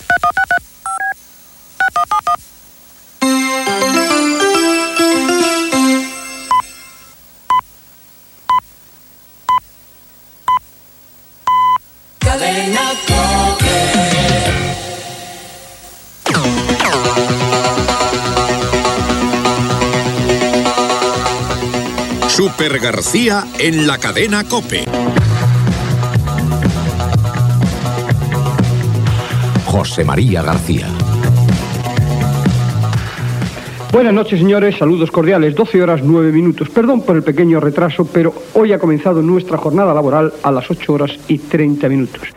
Senyal de desconnexió, senyals horaris, indicatiu de la cadena, careta i presentació de "Supergarcía".
Esportiu